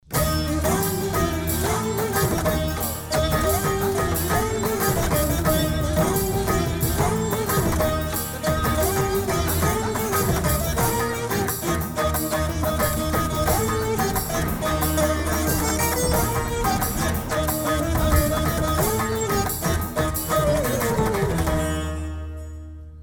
Tamil movie bgm3